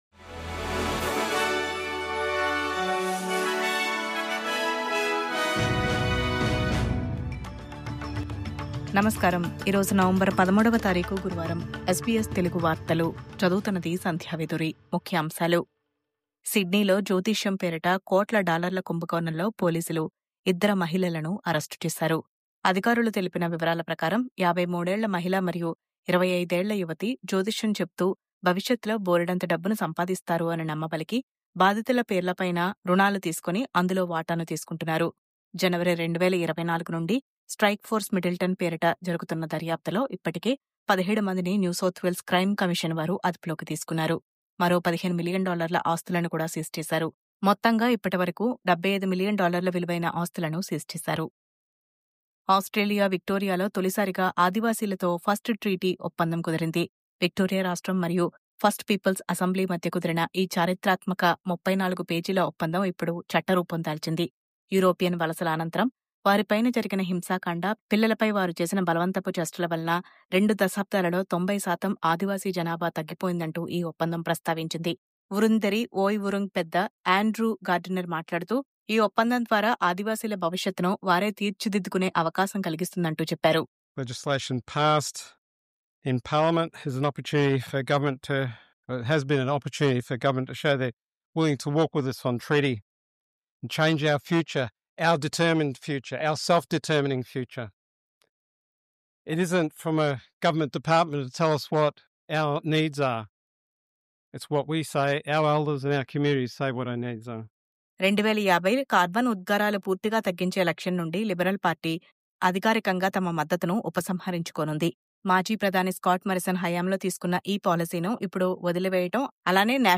News update: జ్యోతిష్యం పేరుతో కోట్ల డాలర్ల కుంభకోణం... 17 మంది నిందితులను అదుపులోకి తీసుకున్న క్రైమ్ కమిషన్..